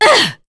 Demia-Vox_Attack5.wav